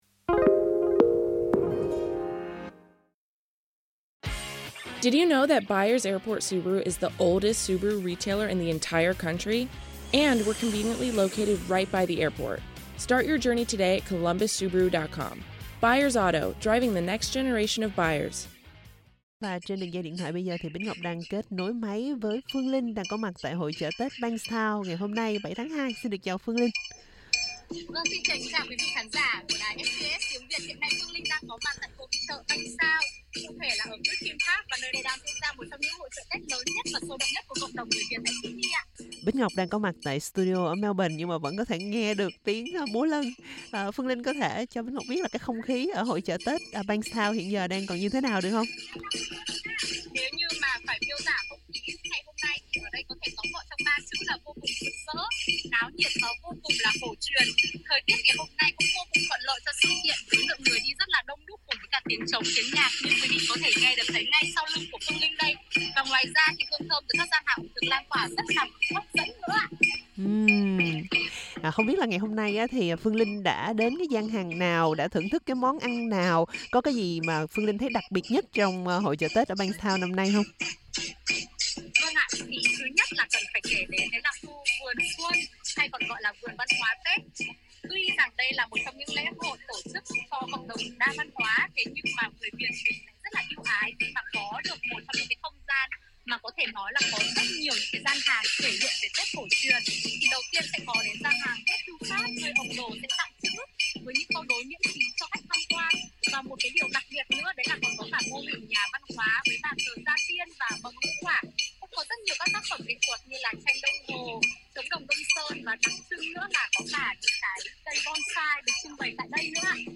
Hàng ngàn người đổ về Griffith Park, Bankstown tham gia một trong những lễ hội Tết sôi động nhất ở khu vực Tây Nam Sydney.